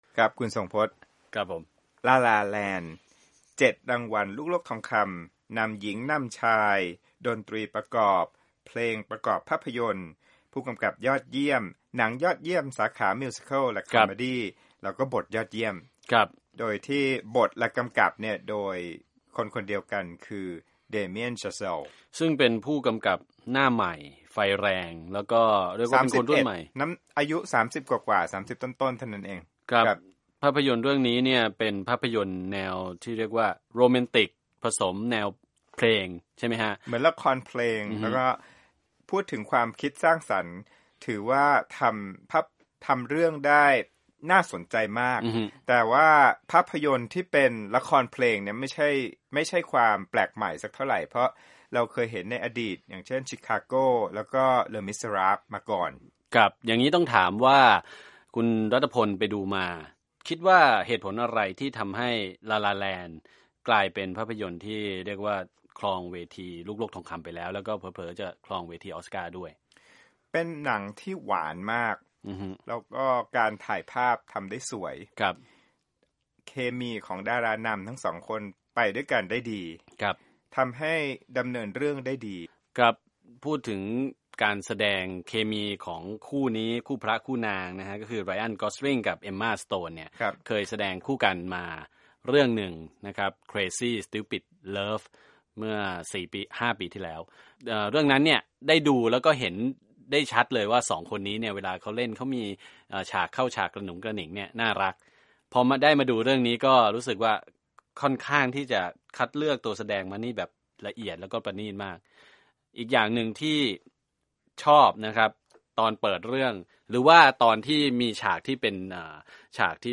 คุยหนัง La La Land